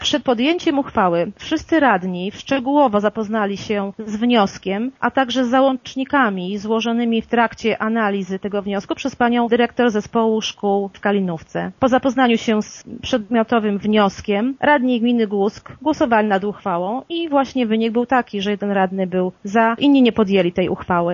Zastępca wójta Urszula Paździor podkreśla, że decyzja zapadła po szczegółowej analizie wszystkich złożonych dokumentów: